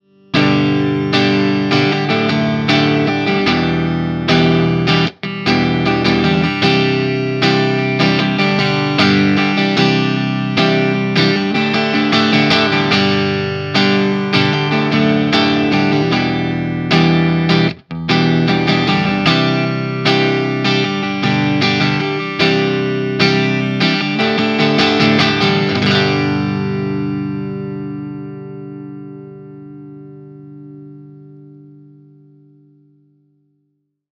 JTM45 Clean Marshall Greenbacks
I must say, though that I really like the greenbacks with this amp/setting.
JTM_CLEAN_MarshallGreenbacks.mp3